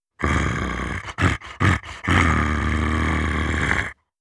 RiftMayhem / Assets / 1-Packs / Audio / Monster Roars / 16.
16. Feral Growl.wav